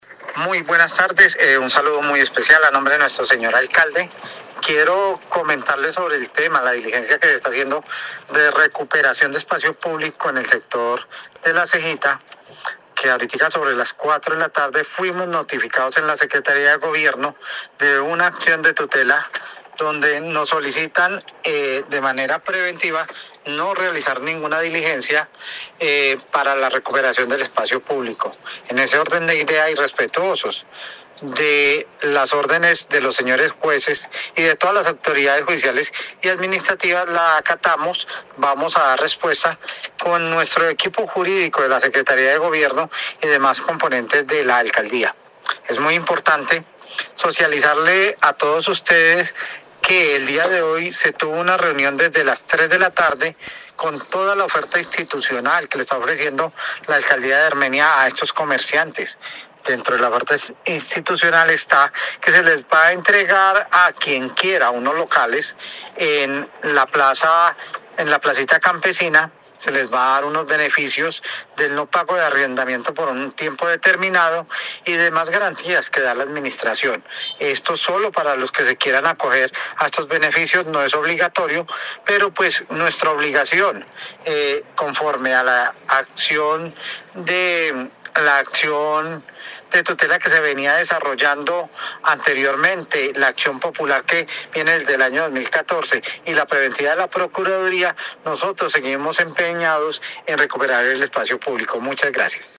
Audio de Carlos Arturo Ramírez Hincapié, secretario de gobierno y convivencia: